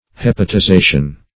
Search Result for " hepatization" : The Collaborative International Dictionary of English v.0.48: Hepatization \Hep`a*ti*za"tion\, n. 1.